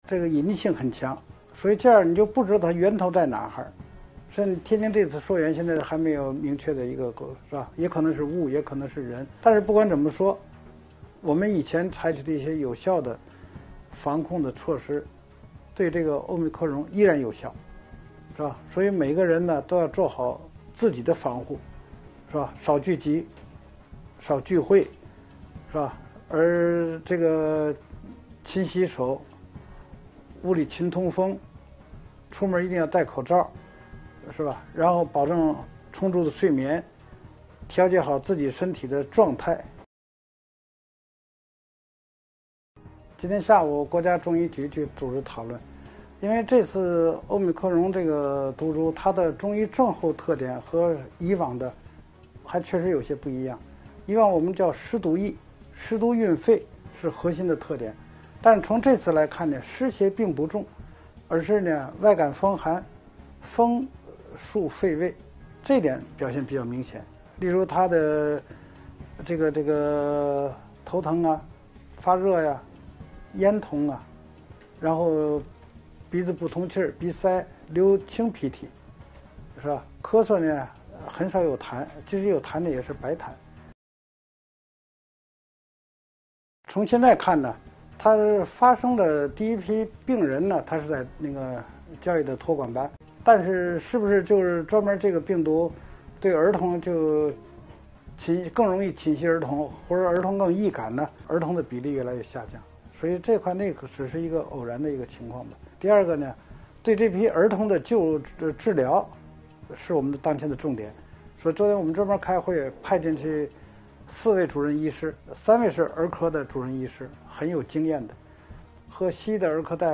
“奥密克戎”是怎么传播的，该如何应对……记者11日专访了中国工程院院士张伯礼。